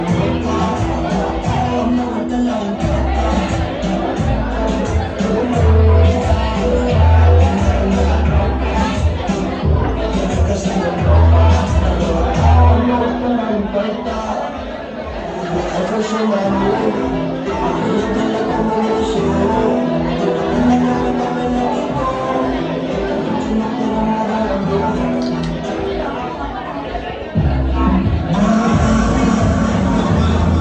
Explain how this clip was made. Chill Out Night Club In Merida On The Weekend